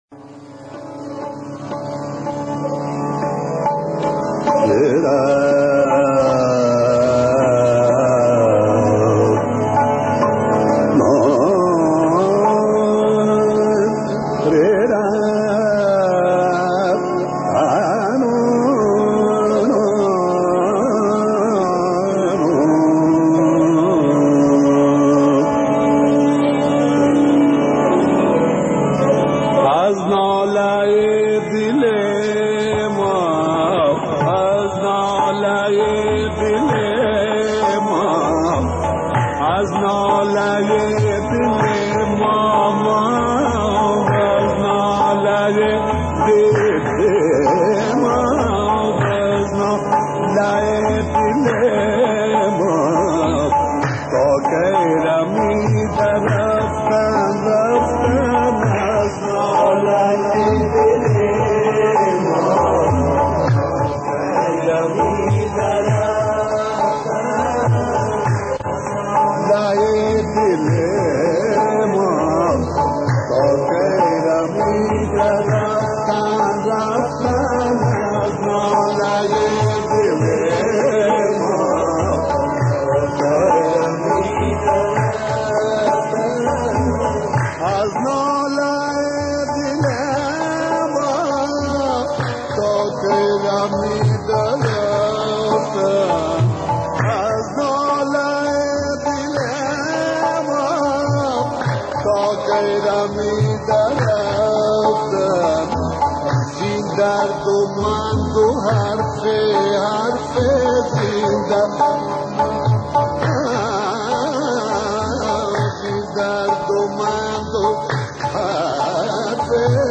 کیفیت اجرا عالی است و شعرهایی که انتخاب شده است نیز.